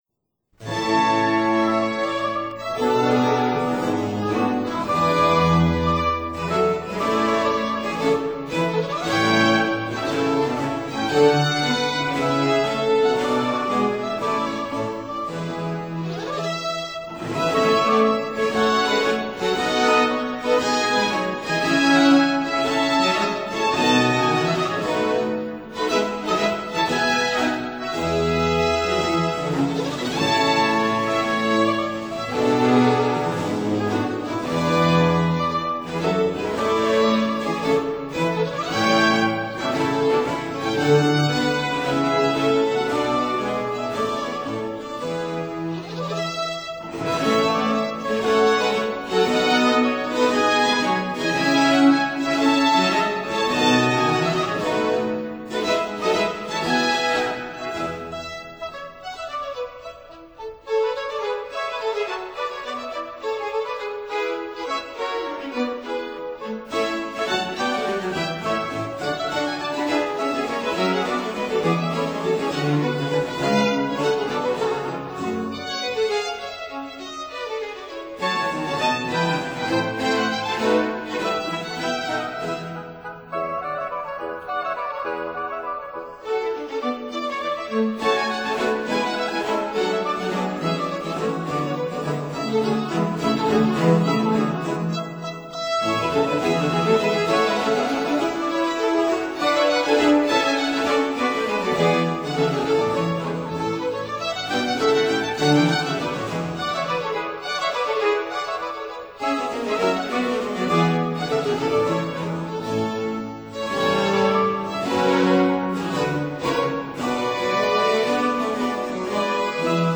Oboe Concerto in G minor, HWV287
Oboe
Violin
(Period Instruments)